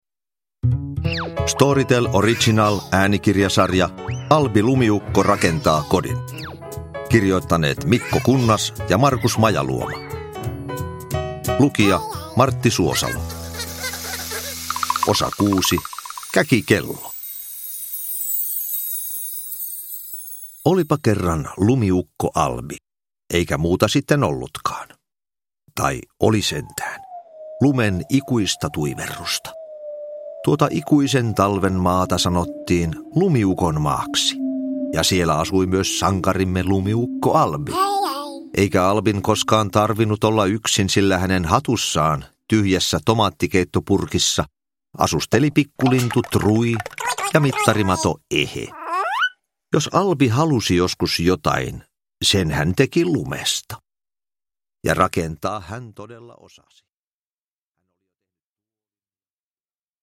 Albi rakentaa kodin: Kello – Ljudbok – Laddas ner
Uppläsare: Martti Suosalo